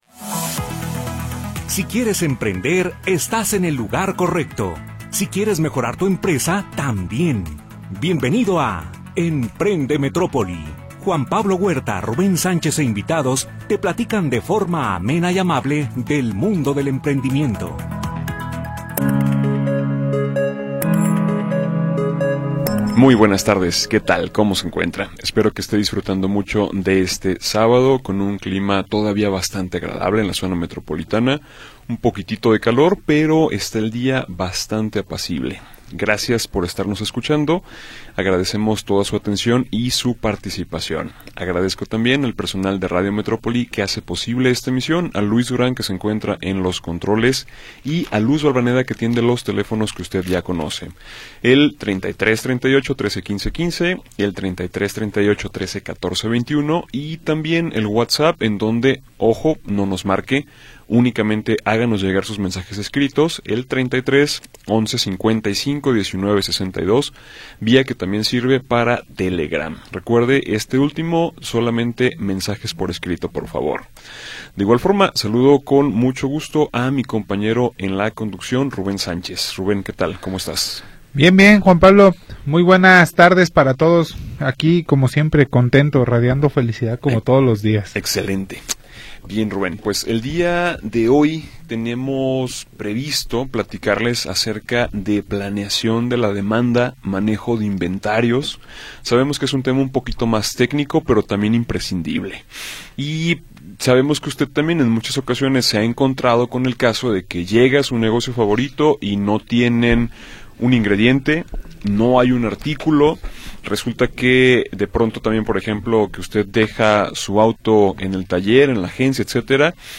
Programa transmitido el 30 de Agosto de 2025.